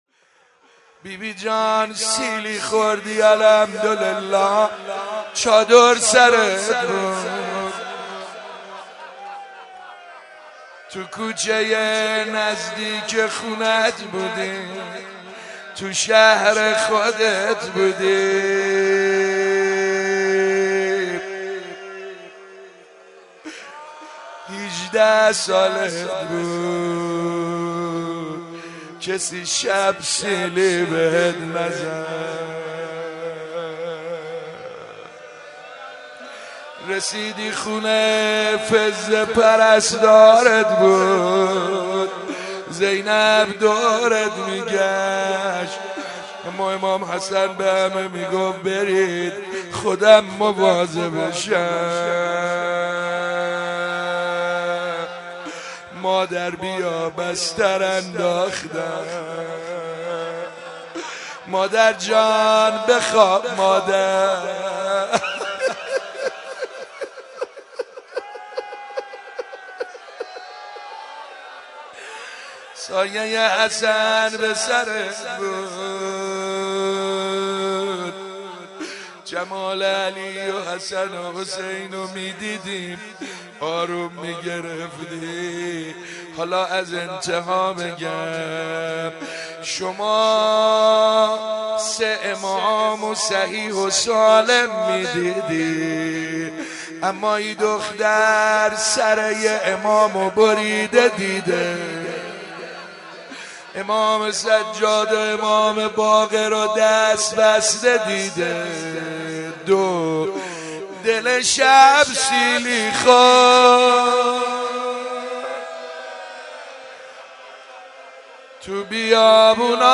زیارت عاشورا 13 اذر مهدیه امام حسن مجتبی(ع)
روضه بخش اول